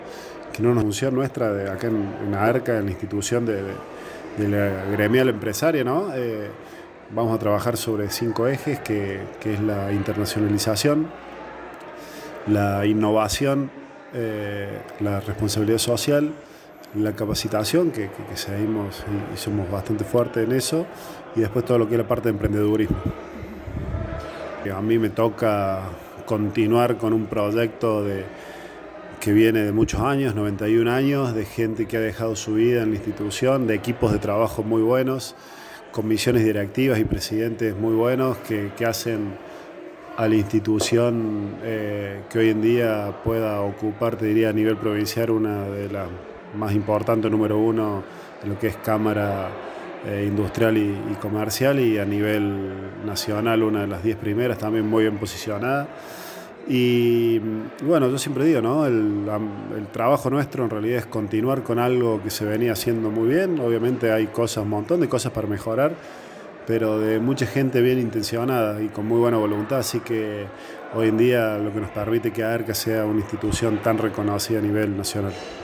Se realizó anoche  en la sede de AERCA un ágape para cerrar el 2021 con la participación de la comisión directiva.